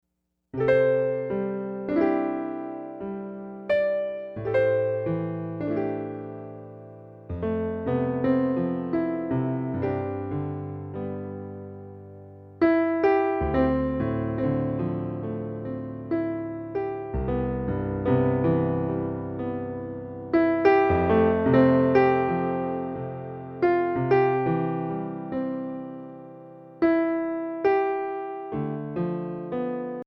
Mp3 Instrumental Song Download